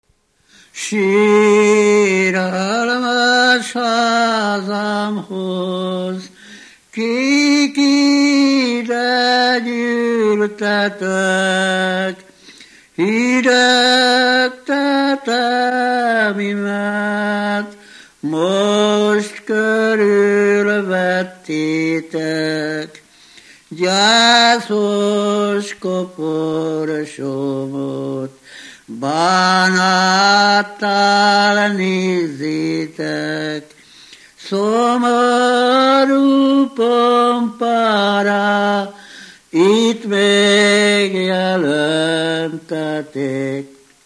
Felföld - Komárom vm. - Naszvad
ének
Műfaj: Virrasztó ének
Stílus: 4. Sirató stílusú dallamok
Kadencia: 4 (2) 2 1